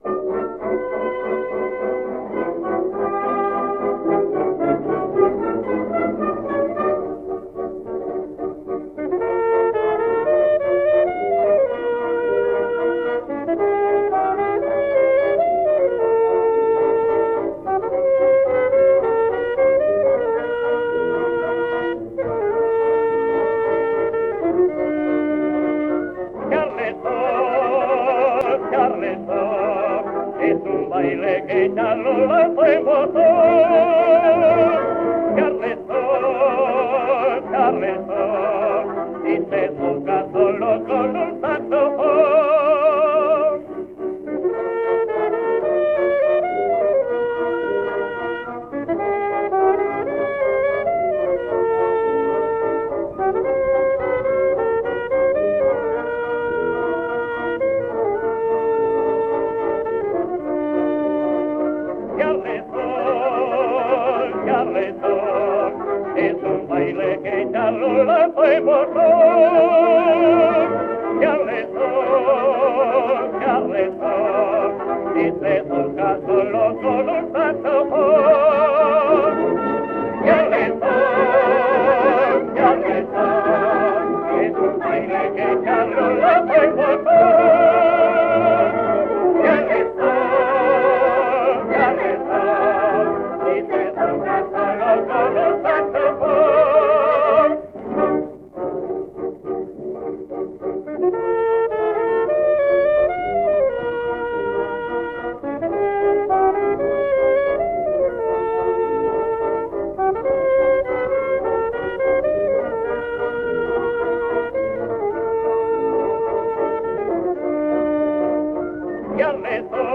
Un barítono y 6 segundas tiples
78 rpm